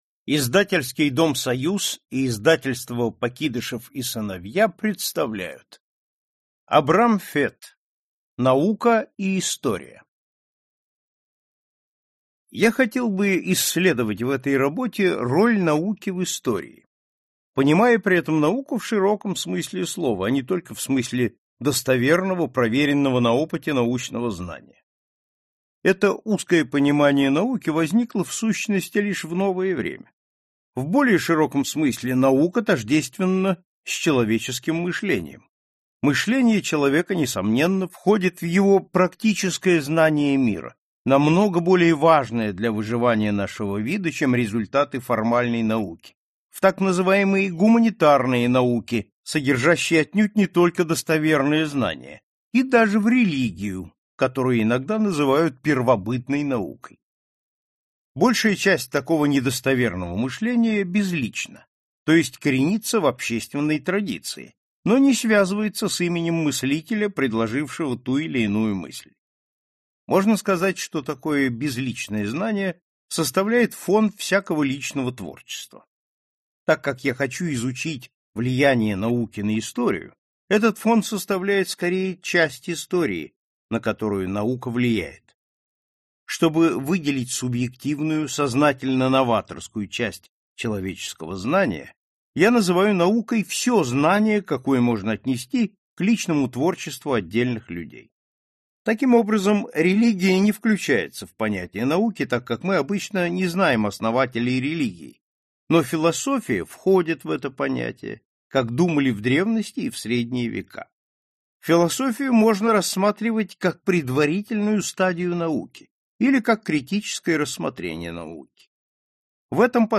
Аудиокнига Наука и история | Библиотека аудиокниг